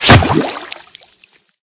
/ gamedata / sounds / material / bullet / collide / water01gr.ogg 25 KiB (Stored with Git LFS) Raw History
water01gr.ogg